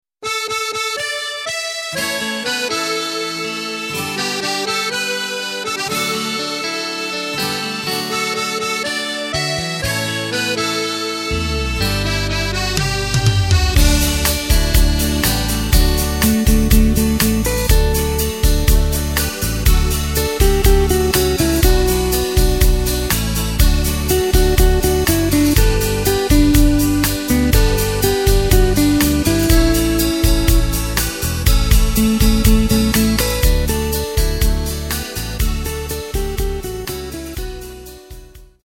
Takt:          4/4
Tempo:         122.00
Tonart:            D
Schlager aus dem Jahr 2008!